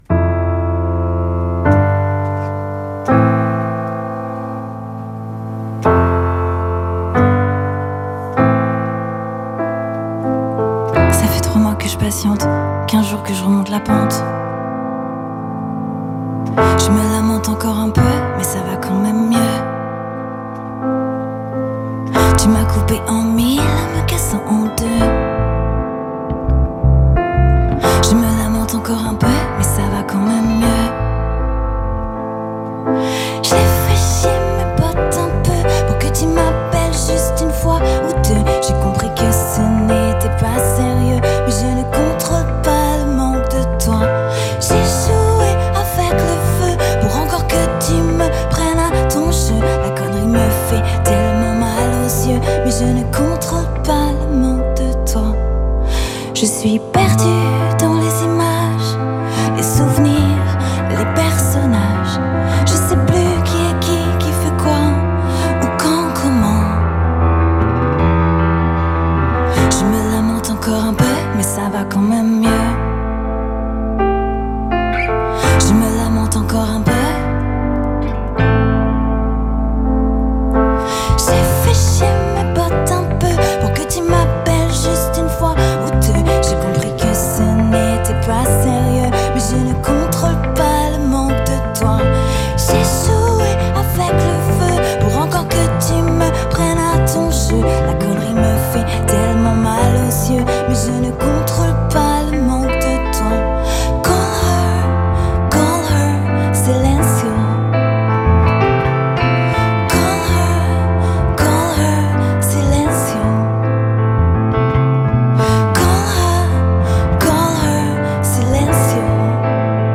A taste of French Pop-Rock to kick off the week.